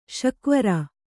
♪ śakvara